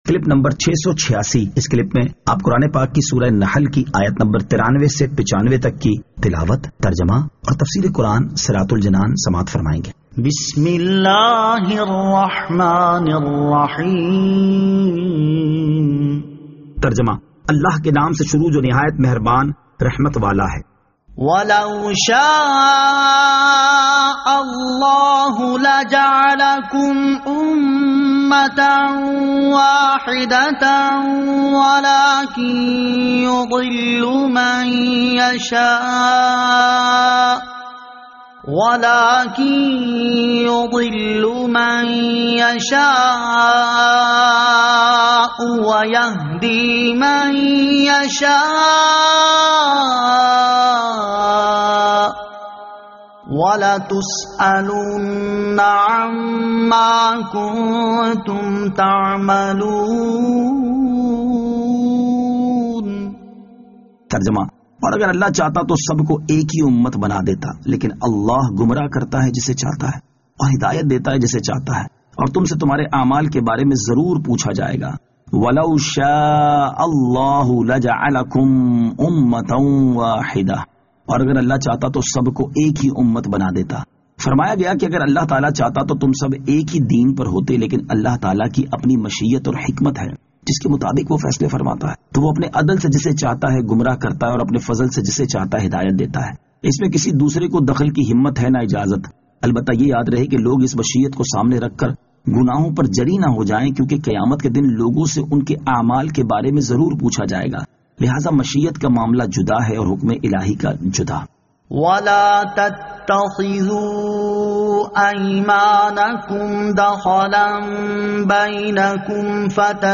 Surah An-Nahl Ayat 93 To 95 Tilawat , Tarjama , Tafseer